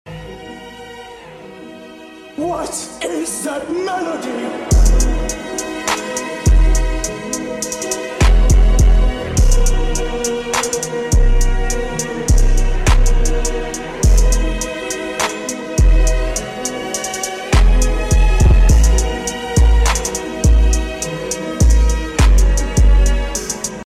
GT3 ASMR